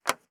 I pick up the receiver of the last telephone box still in operation in metropolitan France. It is in Murbach (68).
Listen : Phone booth pick up #2 (1 s)